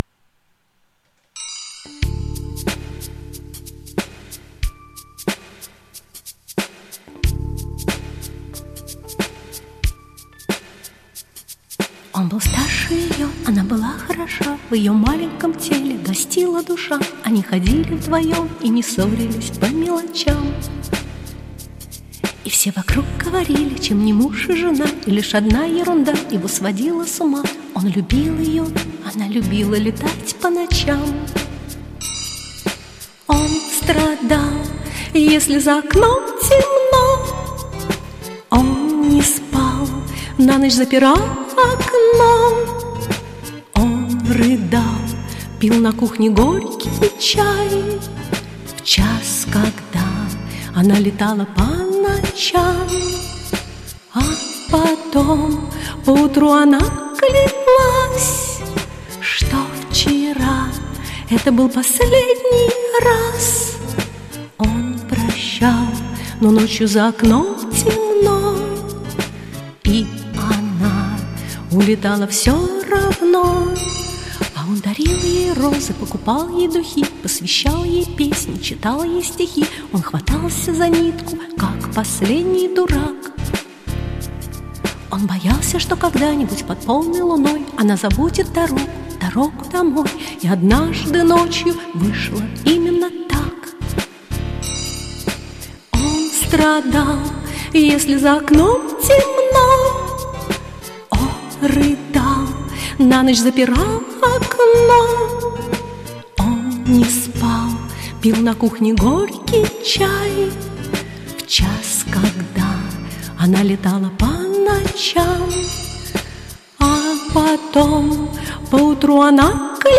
Хороший вокал, интонирование и эмоции.